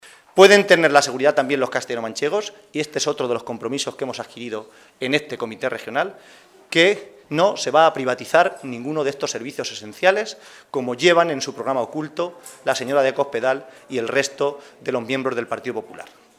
El secretario de Organización del PSOE de C-LM, José Manuel Caballero, ha asegurado hoy, durante la celebración del Comité Regional de este partido, que “los socialistas de C-LM nos hemos conjurado para no dar ni un paso atrás en el mantenimiento y mejora de lo que más importa a los ciudadanos de nuestra Región, que es una sanidad y una educación pública de calidad, y una política social dirigida a los que más lo necesitan”.